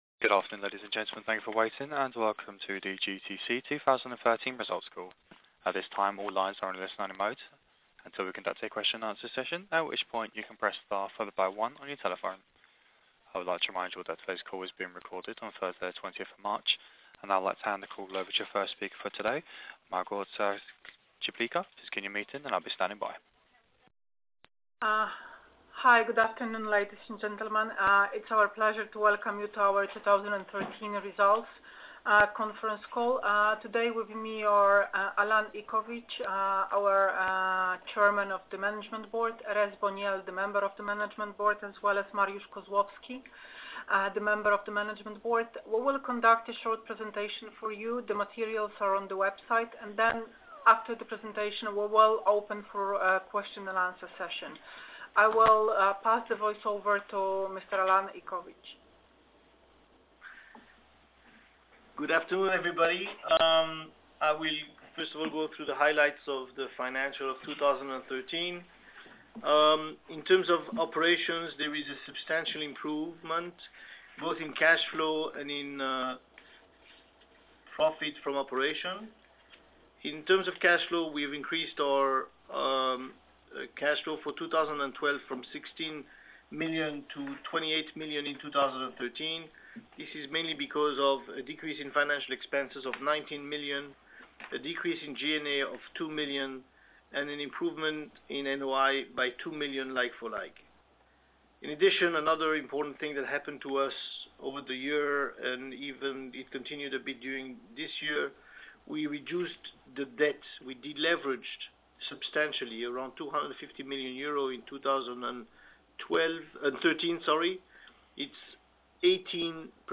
Wyniki za okres 12 miesięcy zakończony 31 grudnia 2013 r. (telekonferencja w języku angielskim)